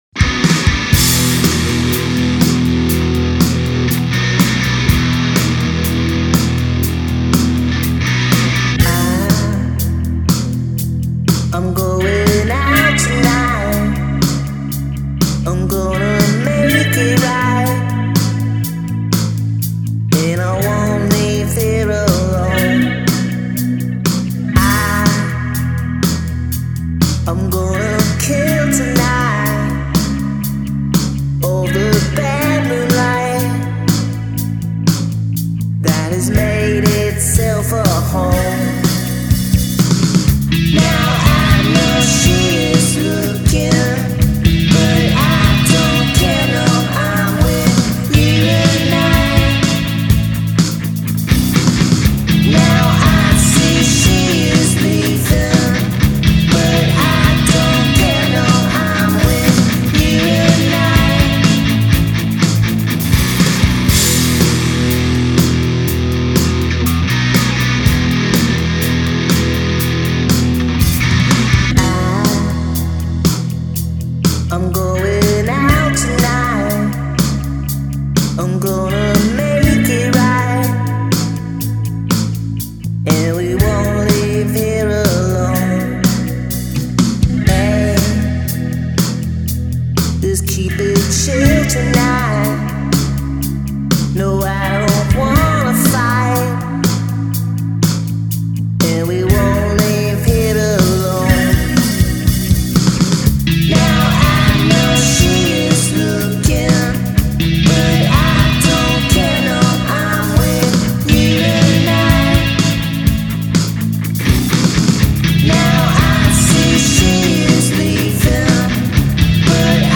late 90s nostalgic rock flavor blended with modern tastes